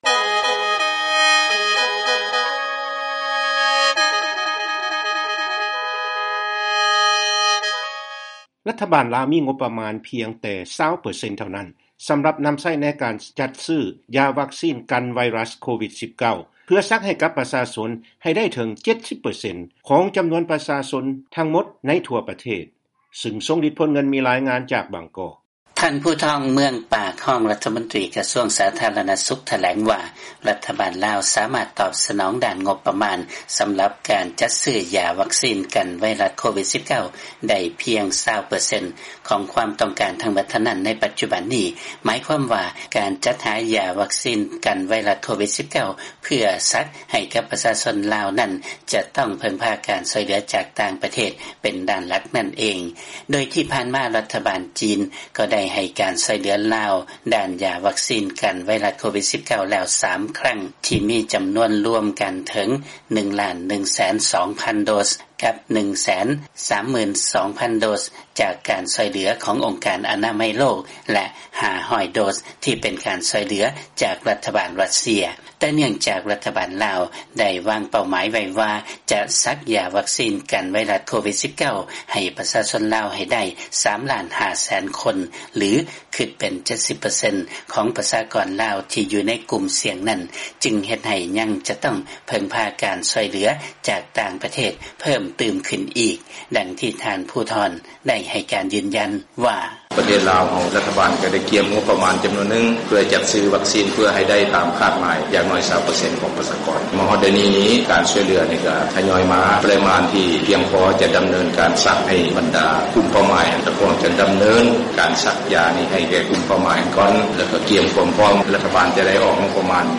ຟັງລາຍງານ ລັດຖະບານ ລາວ ມີງົບປະມານພຽງແຕ່ 20 ເປີເຊັນເທົ່ານັ້ນ ສຳລັບນຳໃຊ້ໃນການຈັດຊື້ຢາວັກຊີນກັນໄວຣັສ COVID-19